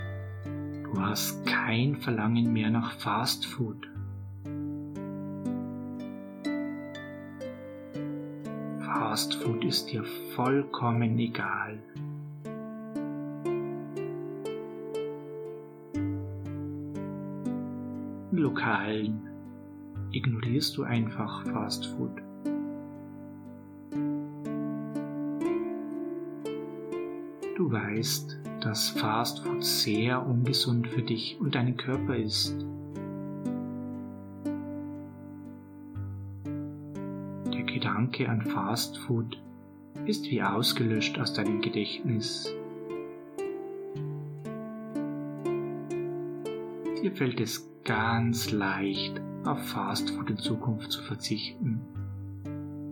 Mit dieser geführten Hypnose werden Sie in Zukunft ganz einfach auf ungesundes Fast Food verzichten können.